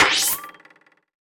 playerHighJump.wav